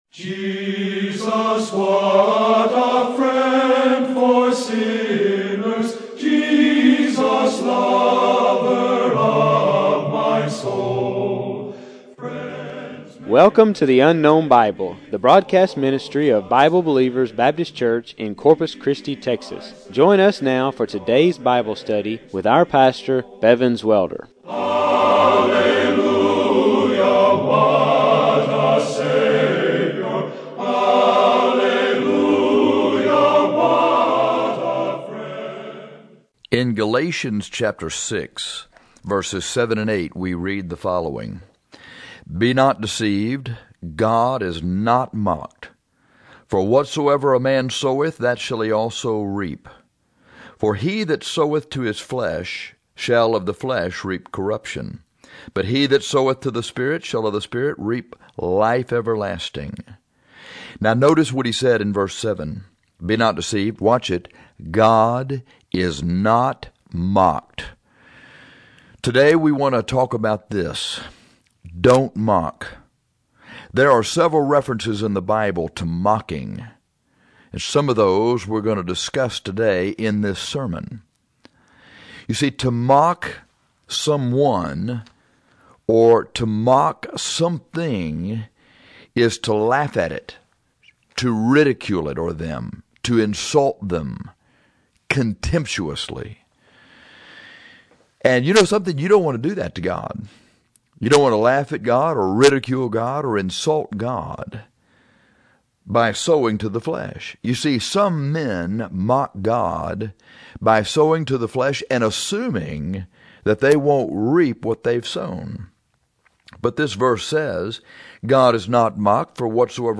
There are several references in the Bible to mocking, some of which we’ll discuss in this sermon.